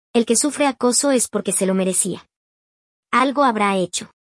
Creado por IA con CANVA